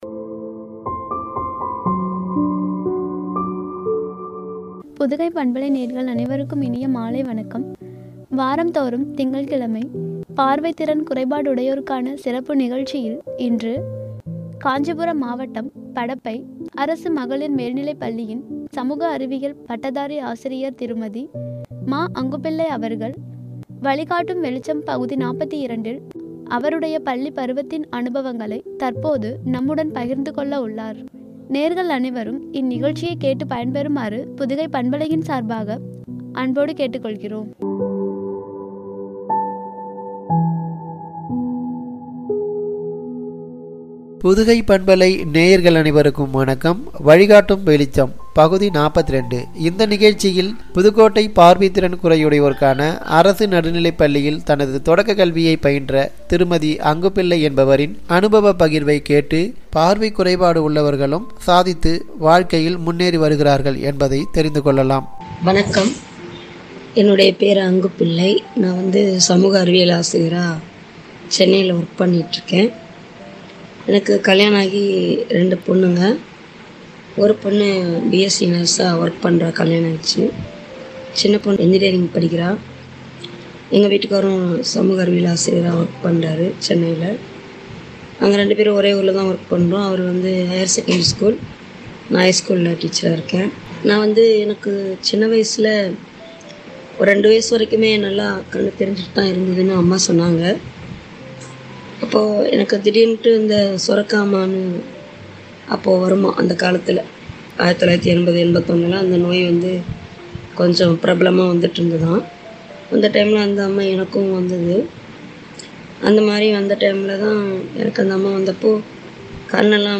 என்ற தலைப்பில் வழங்கிய உரை.